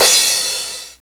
TR909CRSH.wav